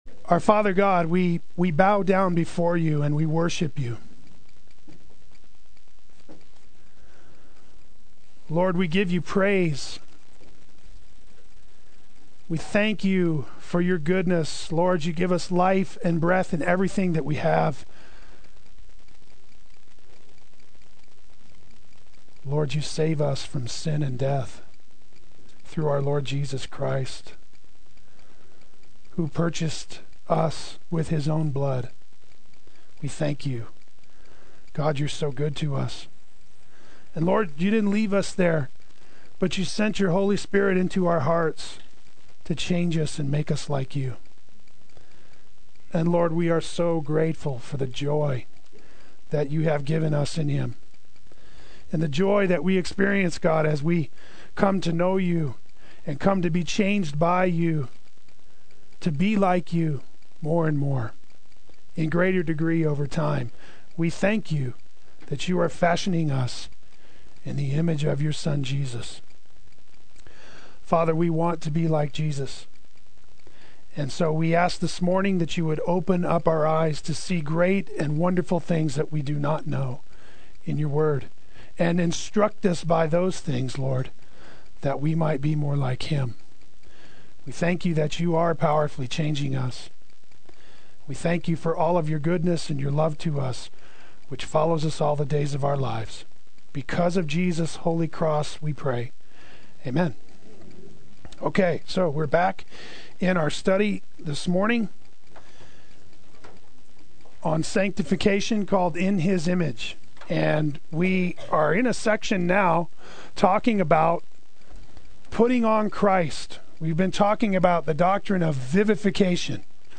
Play Sermon Get HCF Teaching Automatically.
Part 2 Adult Sunday School